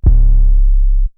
TM88 Dry808.wav